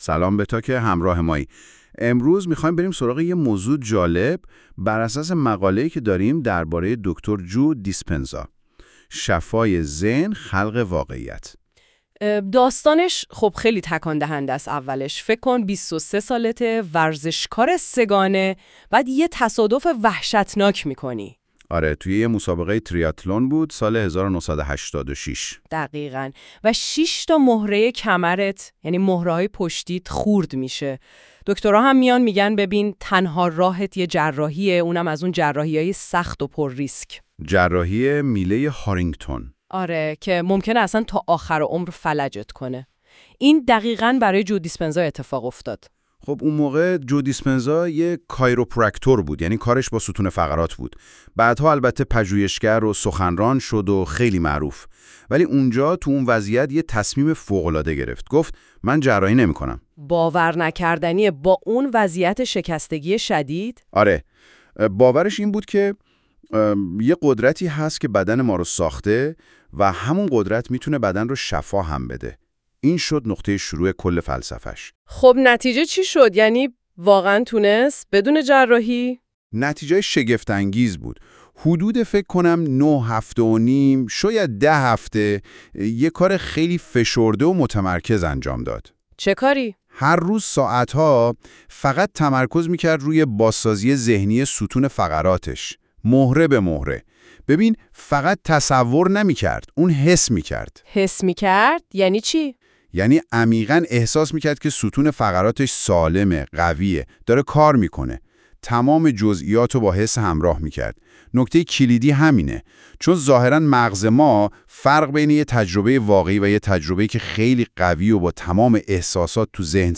‌می‌توانید پادکست صوتی بیوگرافی جو دیسپنزا را که توسط هوش مصنوعی تهیه شده، در اینجا گوش دهید: